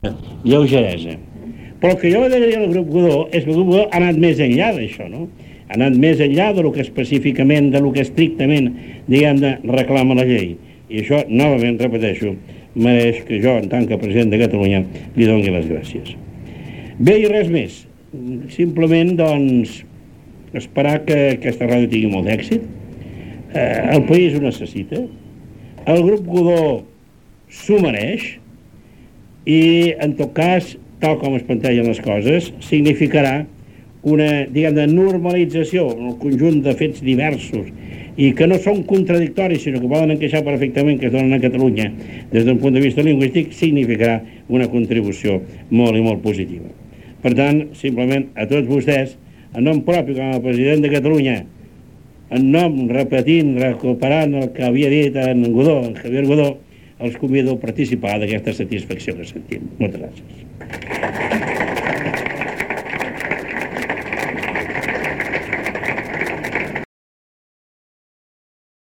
13179b94cd41e3c8a961aac66c298d635cf1506e.mp3 Títol RAC 1 Emissora RAC 1 Barcelona Cadena RAC Titularitat Privada nacional Descripció Paraules finals del discurs del President de la Generalitat Jordi Pujol, el dia de la inauguració de l'emissora.